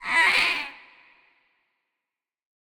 Minecraft Version Minecraft Version 25w18a Latest Release | Latest Snapshot 25w18a / assets / minecraft / sounds / mob / ghastling / death.ogg Compare With Compare With Latest Release | Latest Snapshot
death.ogg